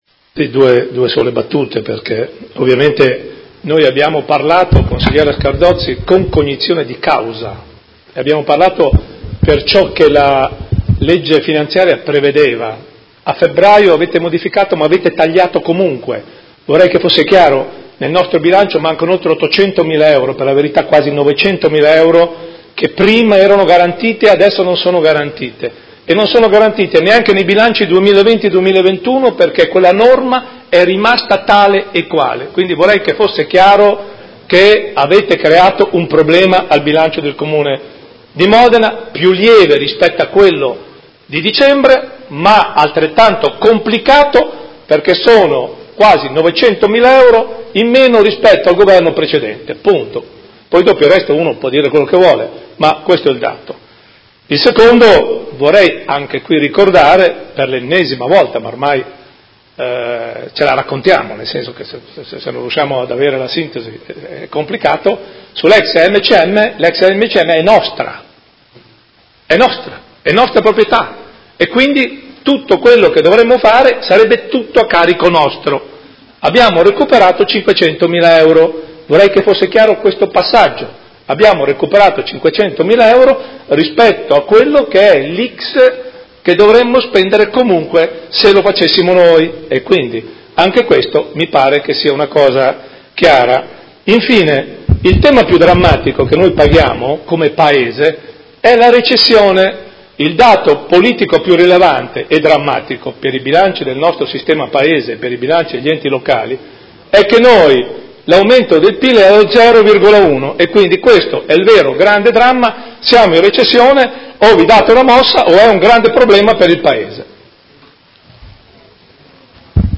Gian Carlo Muzzarelli — Sito Audio Consiglio Comunale
Seduta del 21/03/2019 Replica a dibattito su delibera. Bilancio 2019-2021, programma triennale dei Lavori Pubblici 2019-2021 – Variazione di Bilancio n.1 – Aggiornamento del DUP 2019-2021 – Programma biennale degli acquisti di forniture e servizi 2019-2020